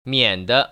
[miăn‧de] 미앤더  ▶